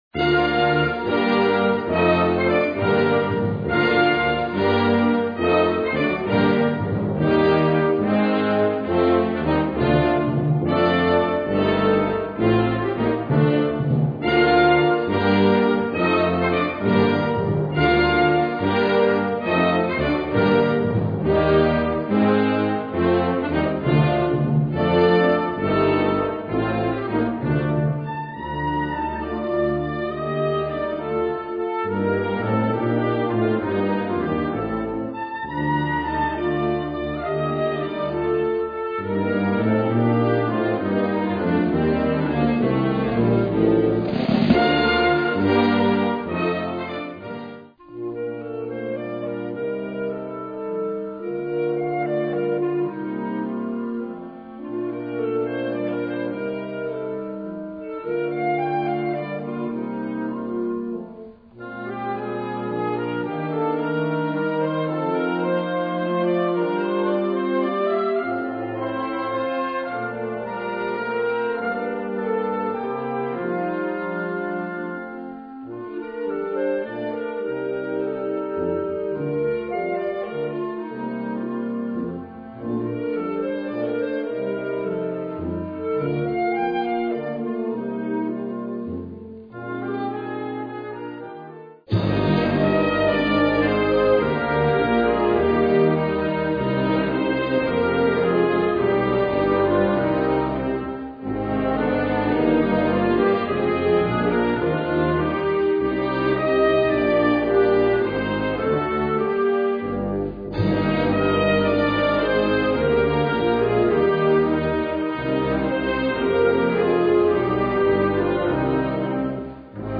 Kategorie Blasorchester/HaFaBra
Unterkategorie Konzertmusik
4 Moderato 1:16